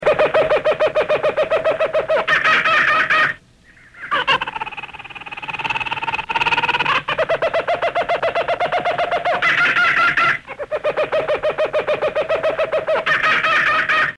Kookaburra's maken lawaai als het bijna donker is, of bij het krieken van de morgen. Ik kan me nauwelijks gezelliger geluid wensen om bij wakker te worden!
kookabura.mp3